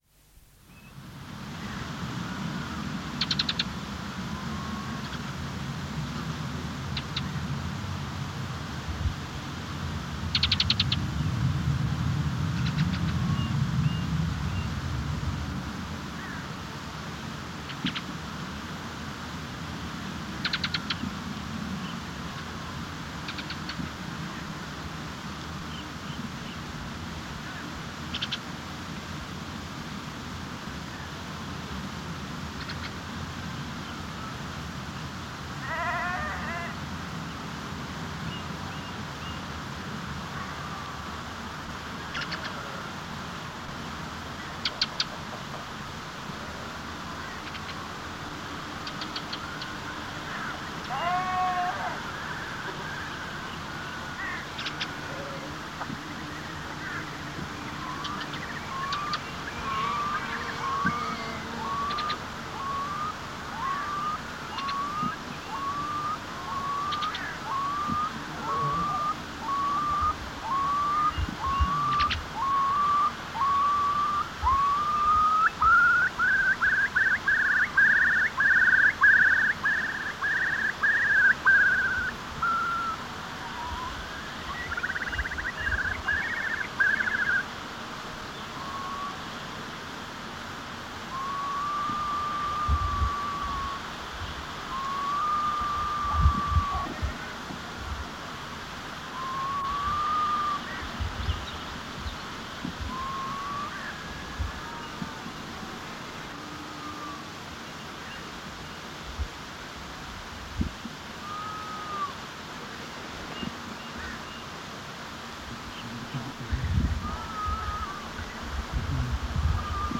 LATE SPRING/SUMMER With ring ouzel alarm calls and song raven, curlew, red grouse, meadow pipit, sheep and flies, also stream in b/g NB: Some bird calls added